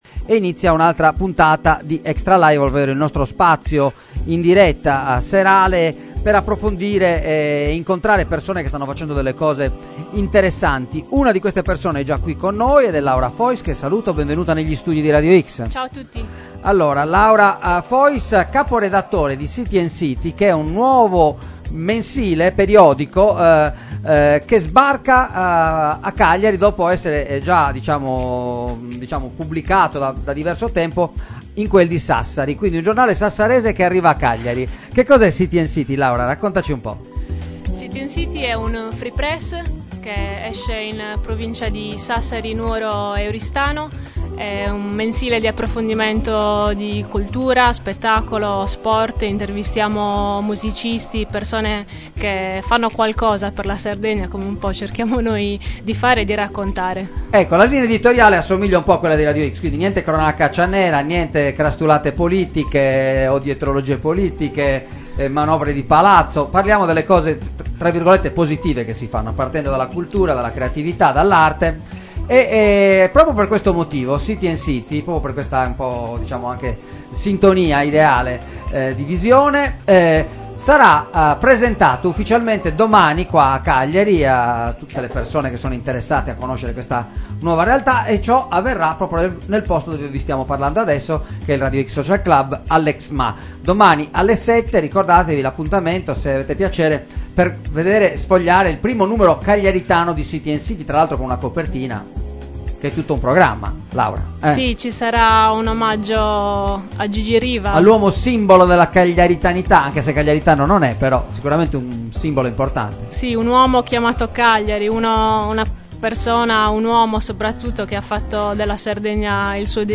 citycityintervista.mp3